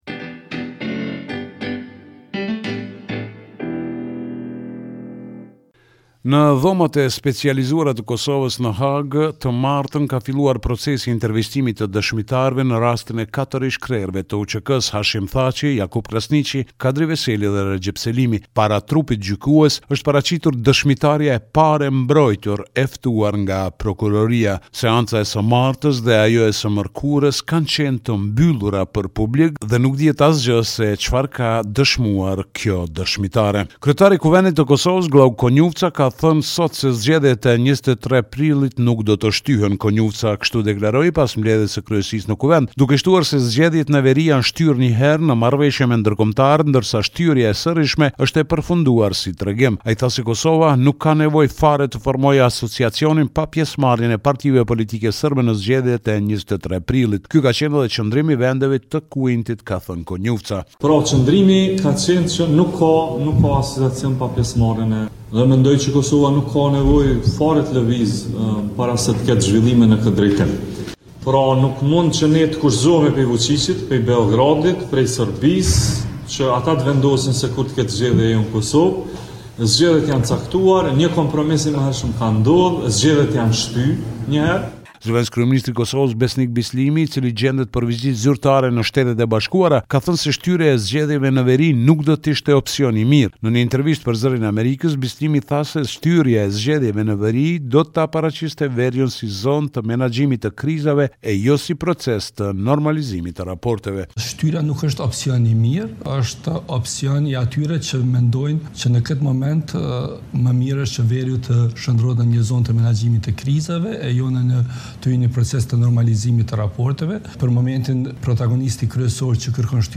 Raporti me të rejat më të fundit nga Kosova.